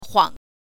huang5.mp3